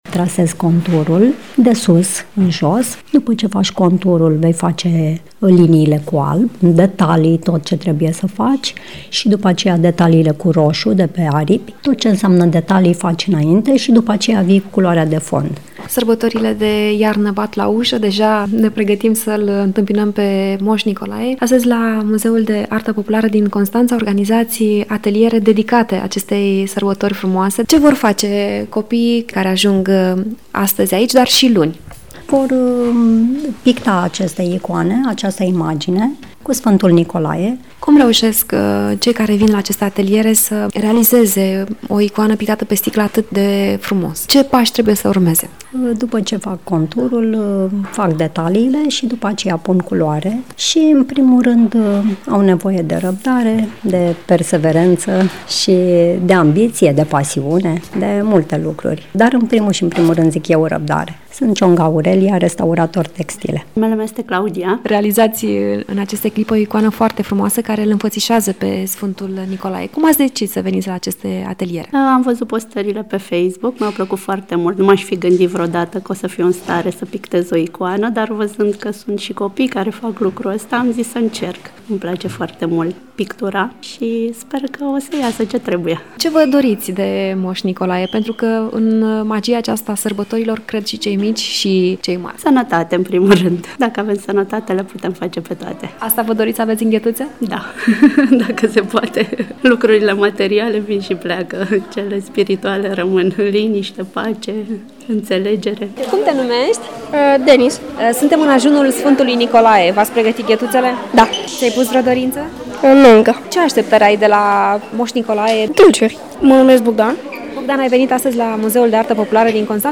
Atelierele de pictură pe sticlă cu tema Sfântul Nicolae – Ocrotitorul copiilor, de la Muzeul de Artă Populară din Constanța au fost un prilej potrivit ca cei mici, dar și cei mari să se gândească la ce își doresc cel mai mult, în zi de sărbătoare.
Îi ascultăm și noi, în Ajun de Moș Nicolae, într-un reportaj